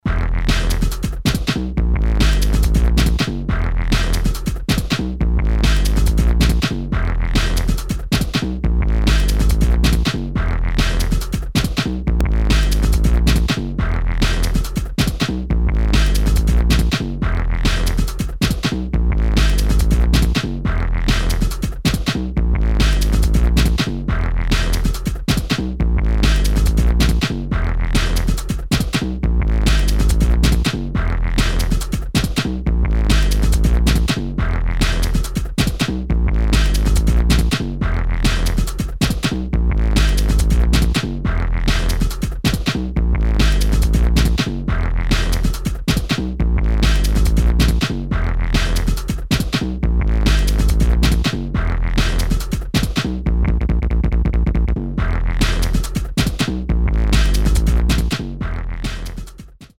NU DISCO | UK GARAGE